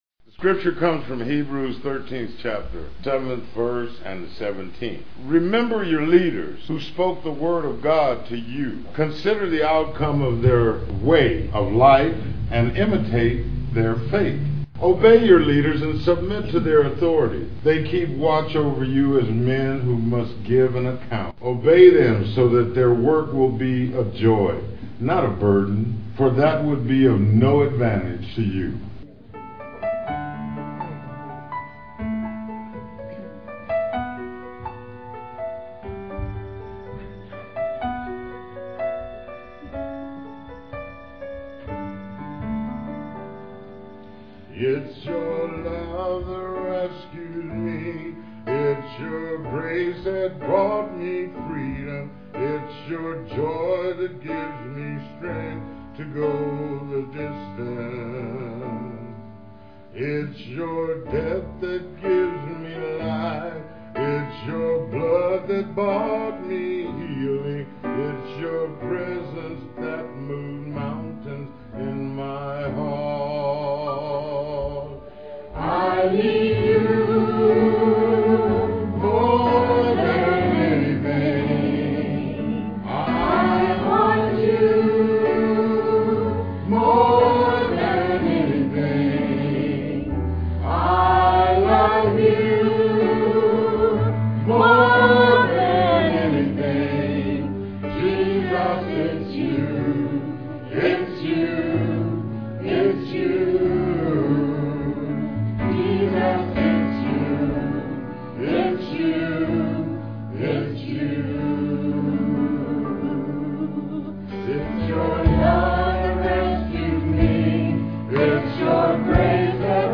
PLAY 10 Commanments for the Home, 5, Jun 4, 2006 Scripture: Hebrews 13:7,17. Scripture Reading and solo